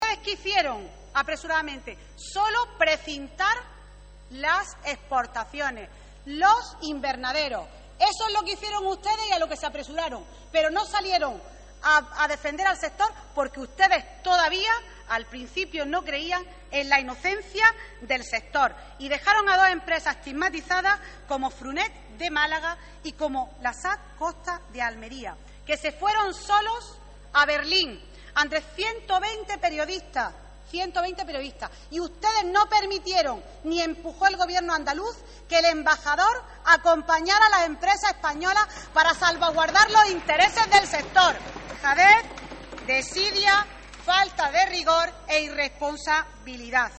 Escuche a Carmen Crespo